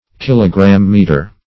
Kilogrammeter \Kil"o*gram*me`ter\, Kilogrammetre